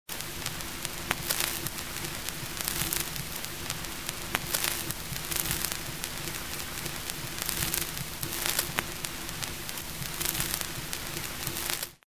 Шум виниловой пластинки